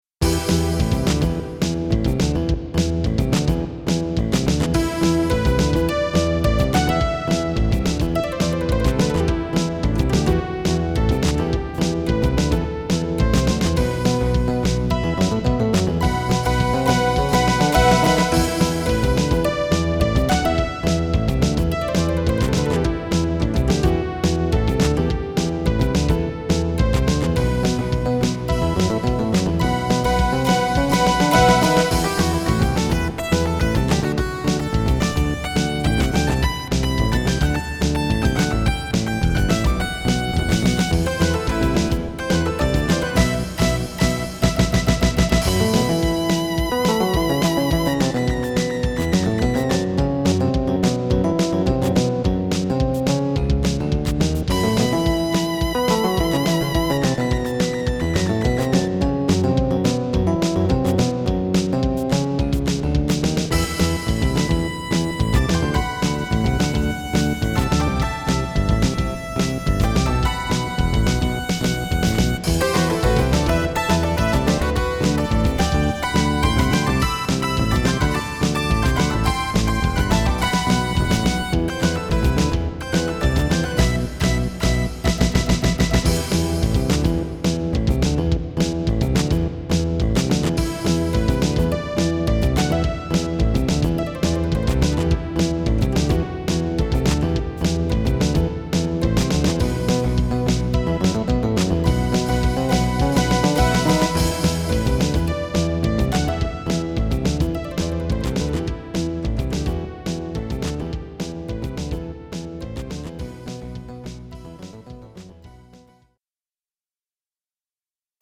MT-32 MIDI conversion
As recorded from the original Roland MT-32 score